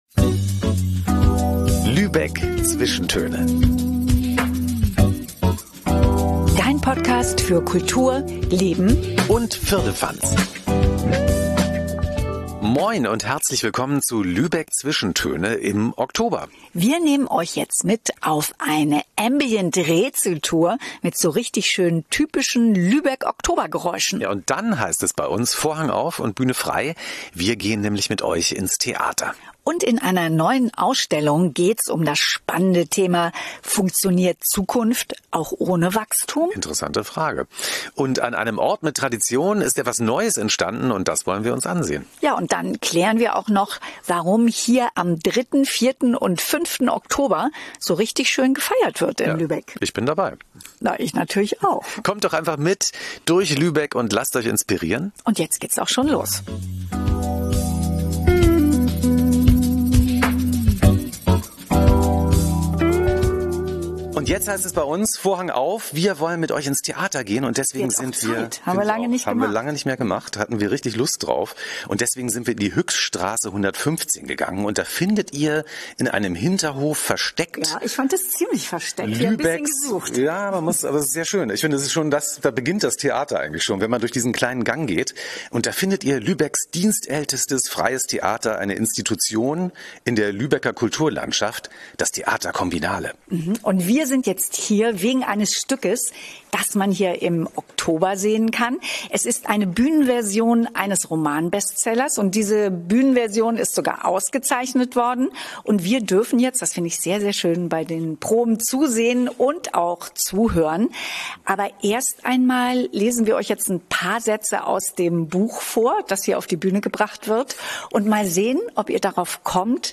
Und wir nehmen dich diesmal mit auf eine Ambient-Rätsel-Tour, mit typischen Lübeck-Oktober-Geräuschen.